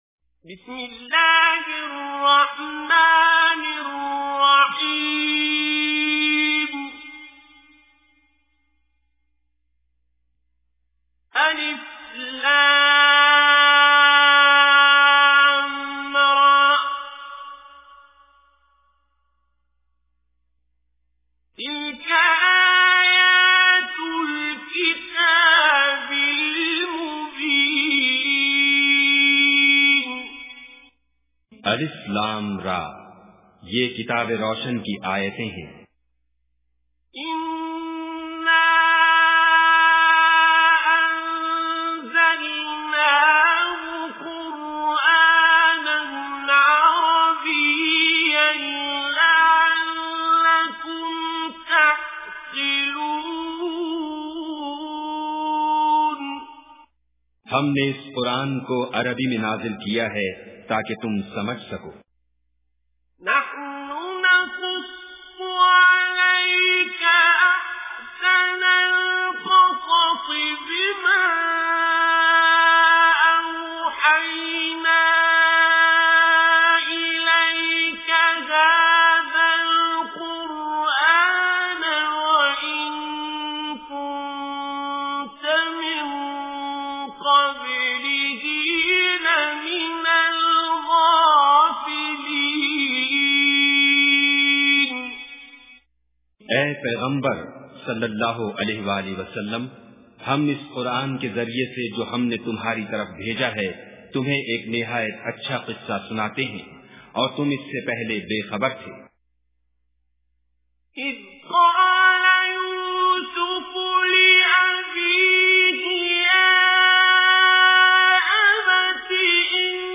Listen online and download beautiful tilawat with urdu translation of Surah Yusuf free from our website.